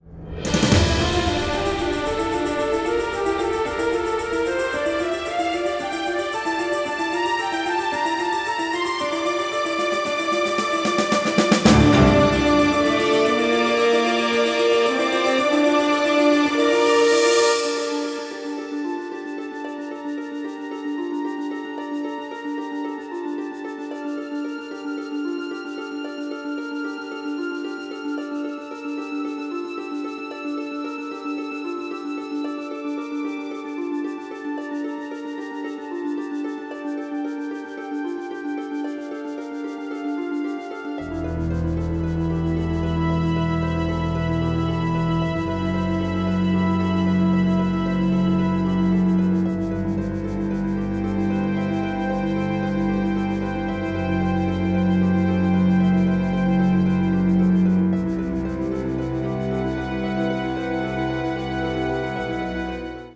entirely composed using electronic elements